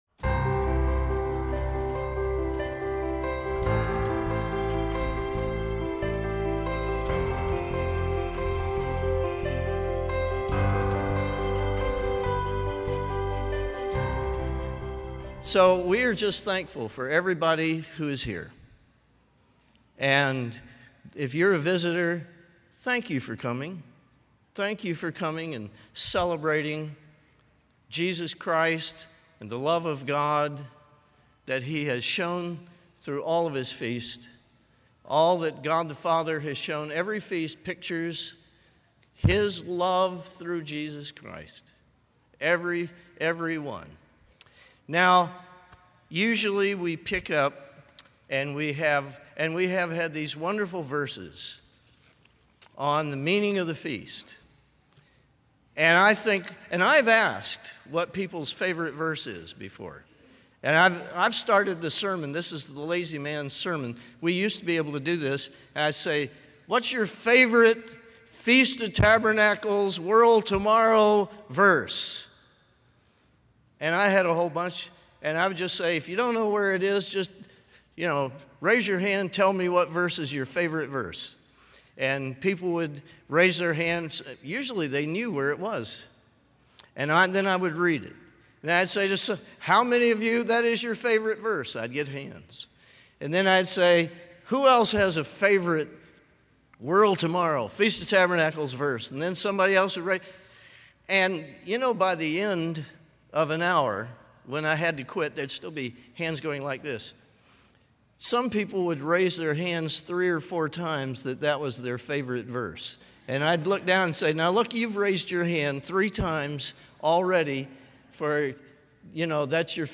This is a message about you, me, and the Glory that God wants us to have.